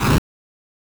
bfxr_fire.wav